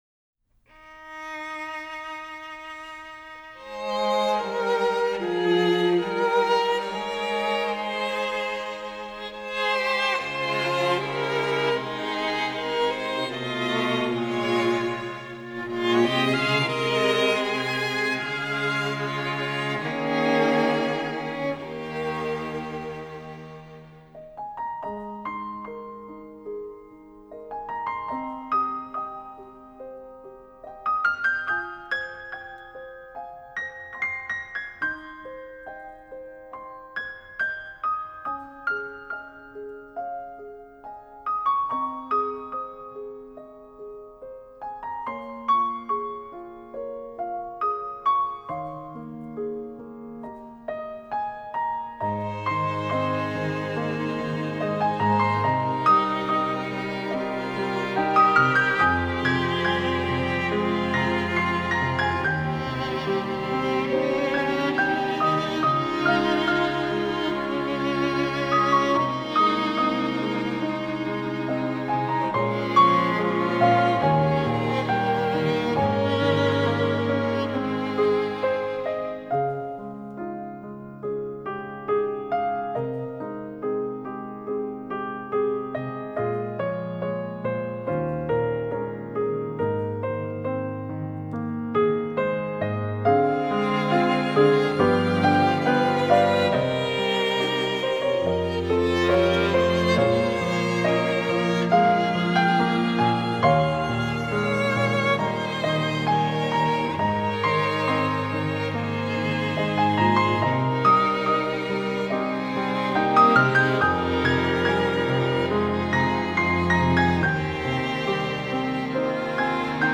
классическая музыка, нью-эйдж